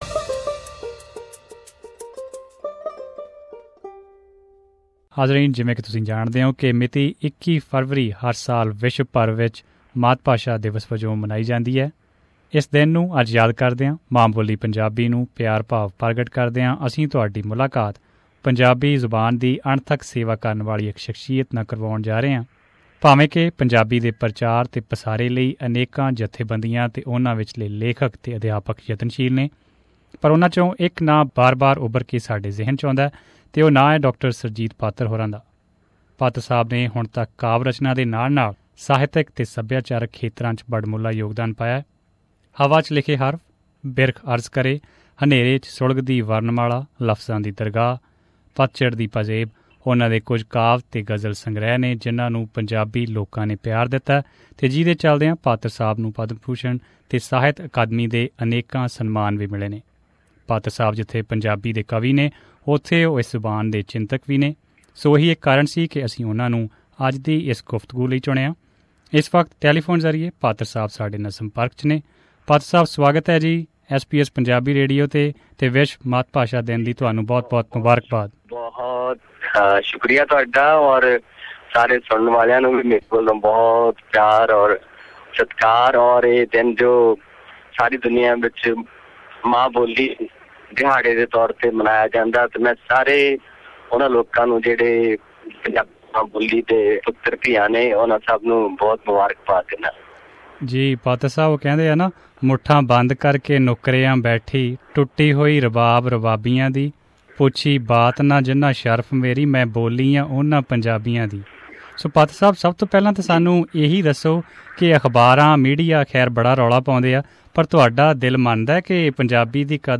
In this conversation with SBS Punjabi, he provides some suggestions on how Non-Resident Punjabis can preserve their mother language, Punjabi.